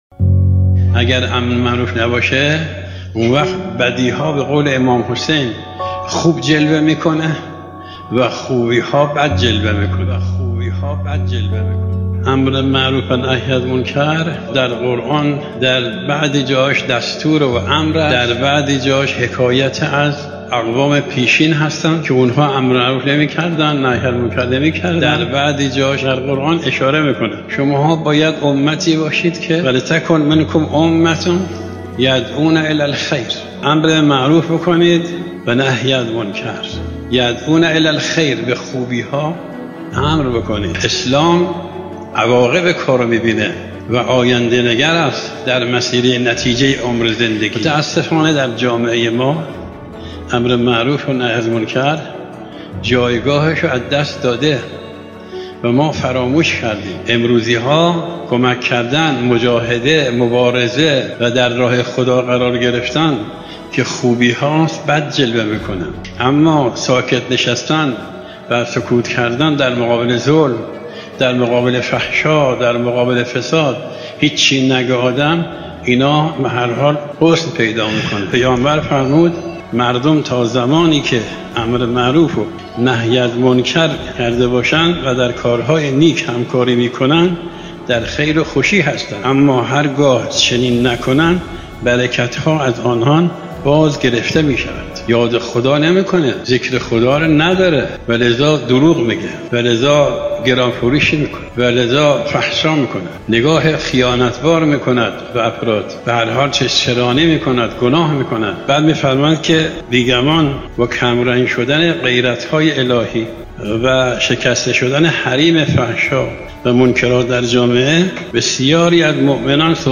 آیت الله محمدرضا ناصری، امام جمعه یزد